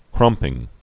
(krŭmpĭng)